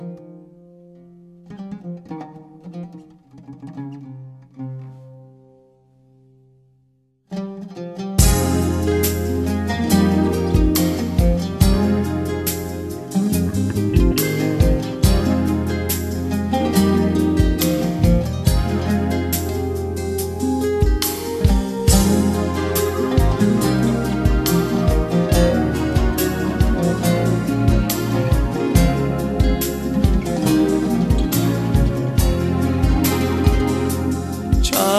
Жанр: Турецкая поп-музыка / Поп